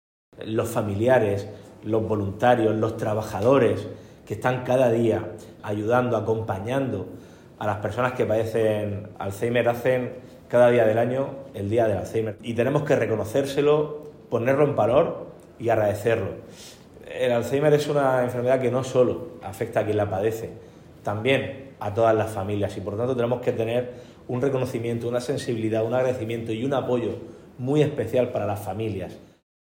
Sonido/ Declaraciones del presidente del Gobierno de la Región de Murcia, Fernando López Miras, sobre la importancia de apoyar a los familiares de pacientes con alzhéimer.